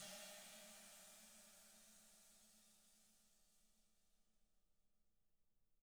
Index of /90_sSampleCDs/ILIO - Double Platinum Drums 2/Partition F/SWISH RIDED